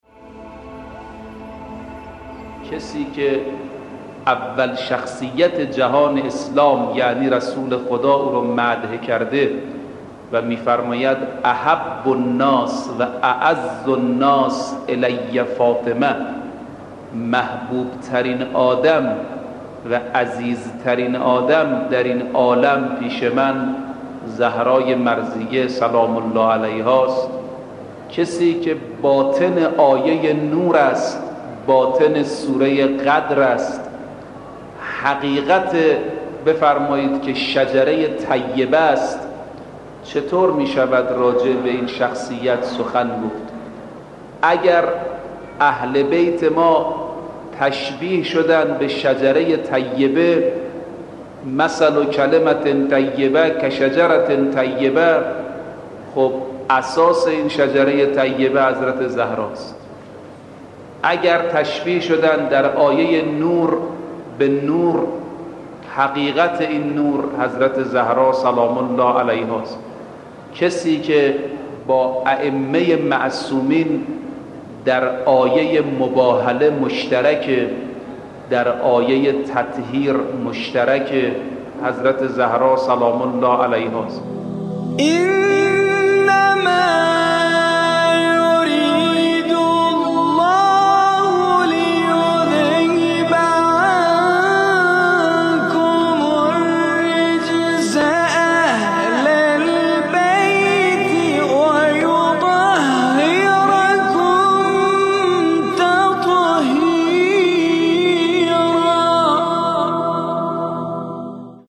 به مناسبت ایام فاطمیه مجموعه پادکست «نگین شکسته» با هدف بیان بندگی و فضائل حضرت فاطمه زهرا(س) با کلام اساتید بنام اخلاق به کوشش ایکنا گردآوری و تهیه شده است، که هجدهمین قسمت این مجموعه با کلام حجت‌الاسلام رفیعی با عنوان «حضرت زهرا(س)؛ باطن سوره نور» تقدیم مخاطبان گرامی ایکنا می‌شود.